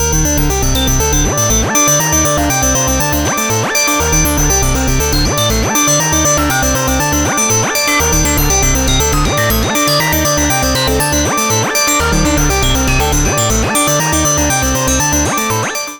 Here’s 1 track, 1 phrase and 1 FM instrument with different wavetables in the operators.